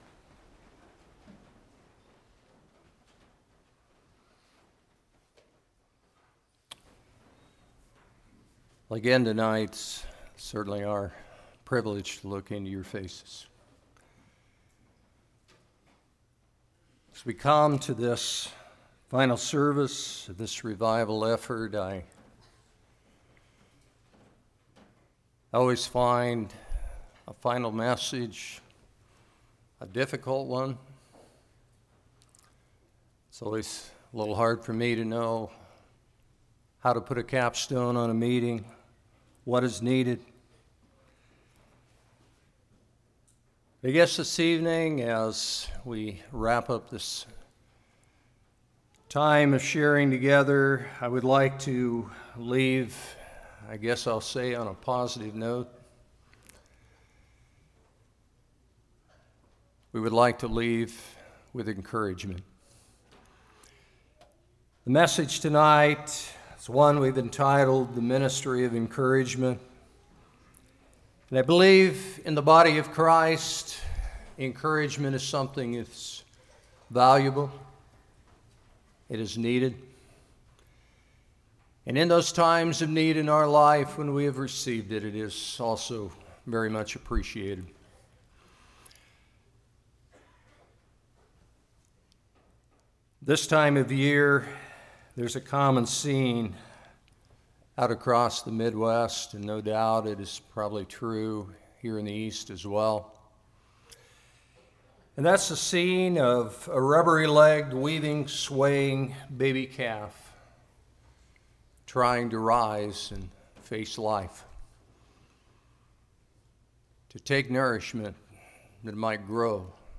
Hebrews 10:19-25 Service Type: Revival Who should encourage?